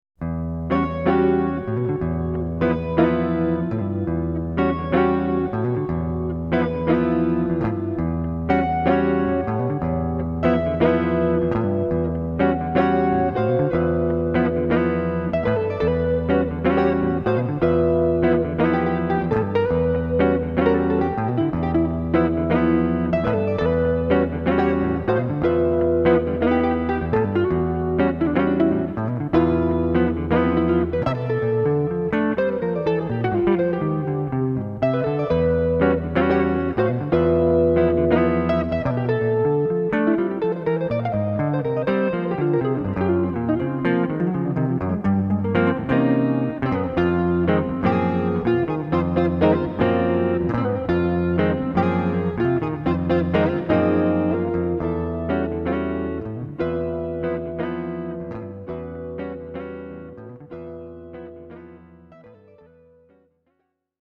jazz_intro.mp3